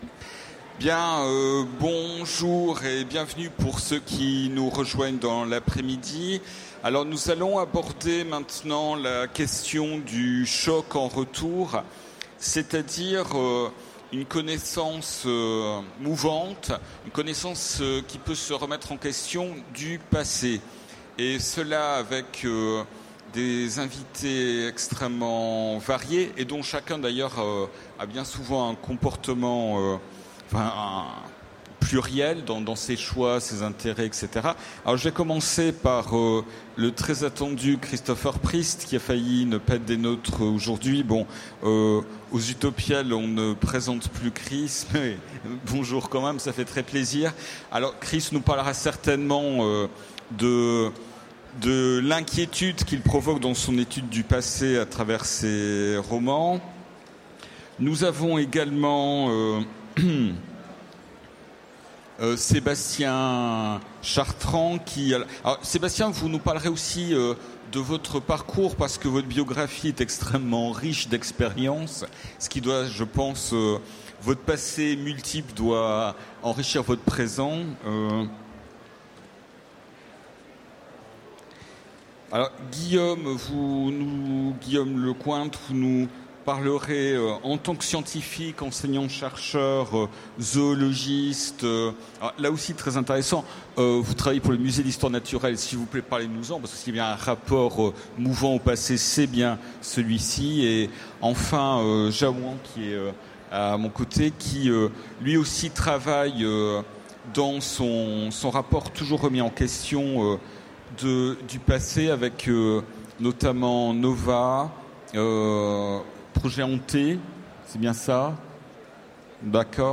Utopiales 2015 : Conférence Et si une découverte archéologique modifiait notre interprétation du passé ?